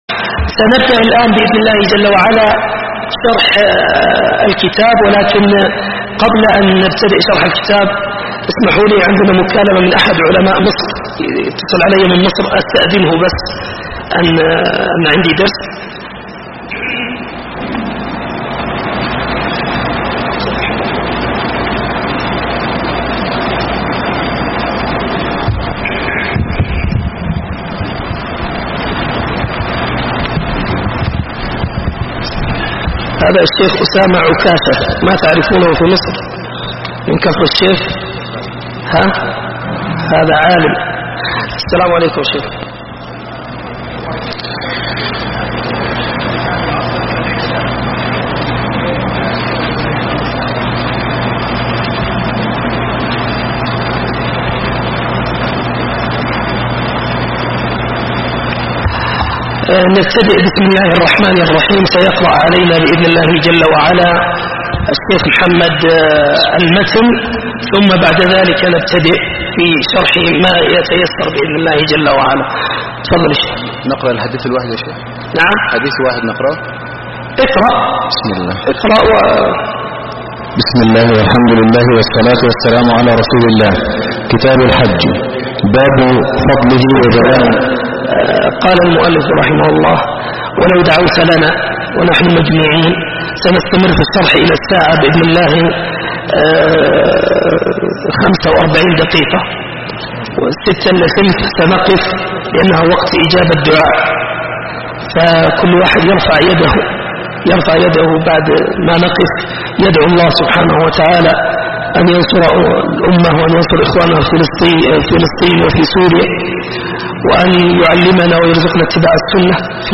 دروس وسلاسل شرح كتاب الحج من بلوغ المرام